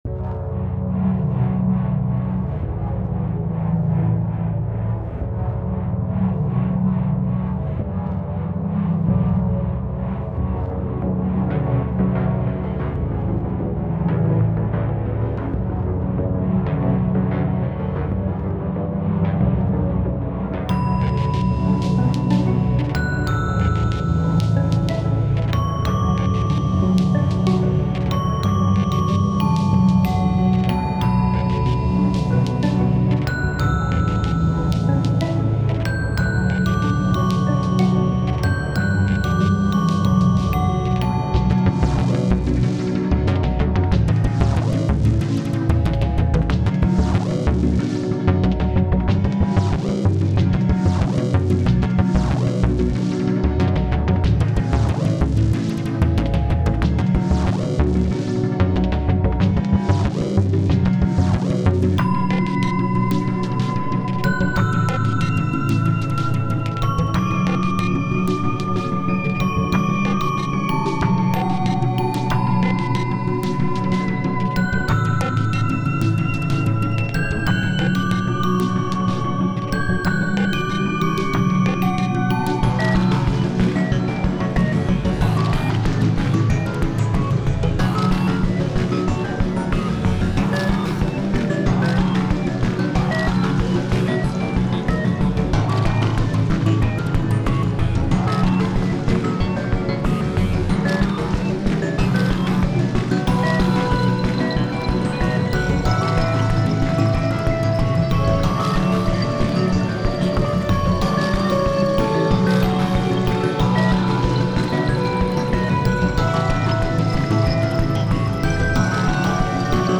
水中×機械のフィールド楽曲。